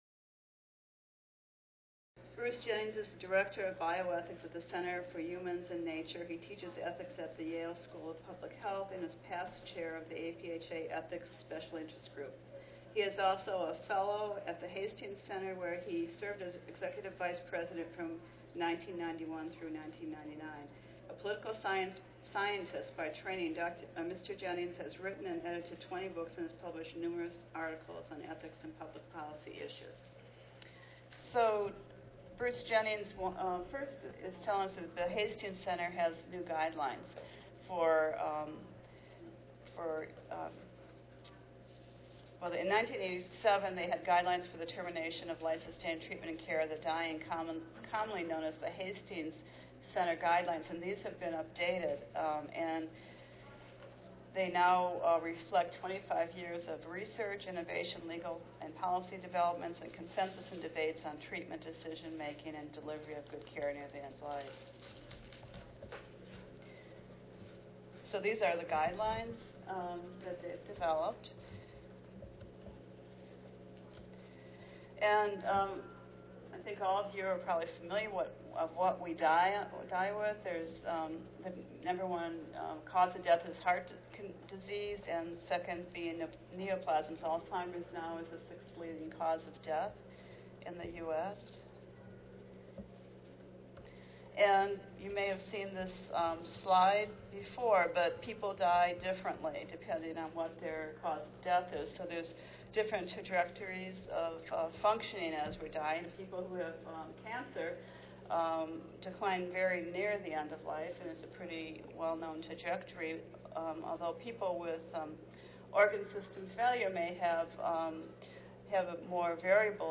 4080.1 Weight Rights to Choose at the End of Life: Major Points of Consensus and Disagreement Tuesday, October 30, 2012: 10:30 AM - 12:00 PM Oral This interdisciplinary panel presentation and discussion aim to explore the major points of consensus and disagreement in weighing rights to choose palliative and end-of-life options at the end of life. In New York, a recently enacted law creates a right to palliative care for patients with terminal illness, and mandates that attending health care practitioners offer to provide information and counseling to such patients.